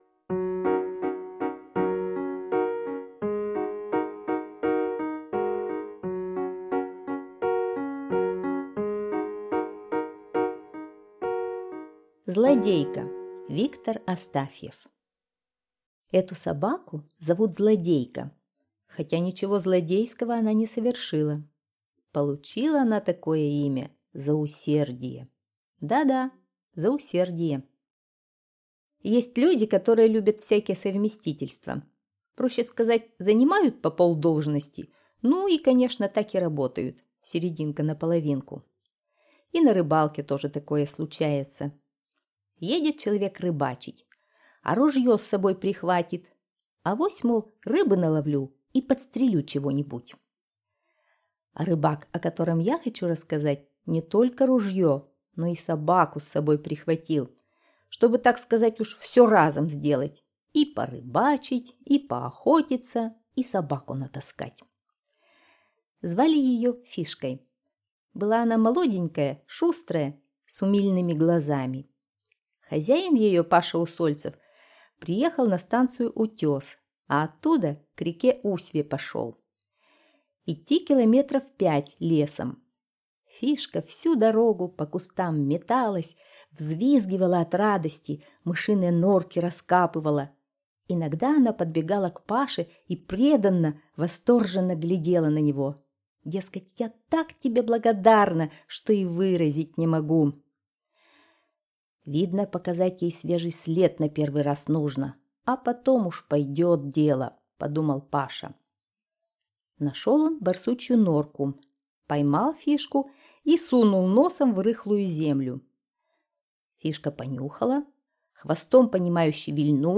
Аудиорассказ «Злодейка»